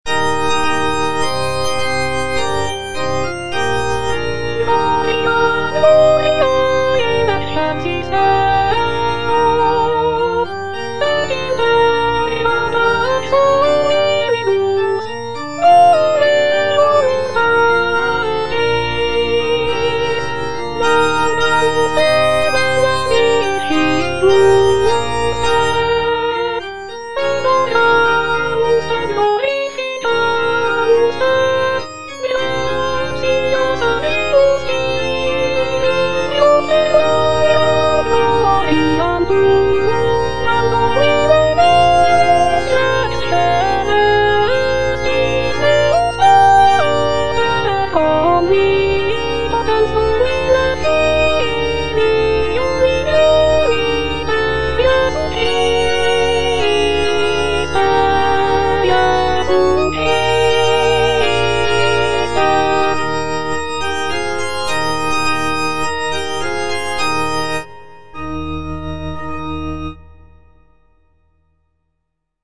G. FAURÉ, A. MESSAGER - MESSE DES PÊCHEURS DE VILLERVILLE Gloria - Alto (Emphasised voice and other voices) Ads stop: auto-stop
The composition is a short and simple mass setting, featuring delicate melodies and lush harmonies.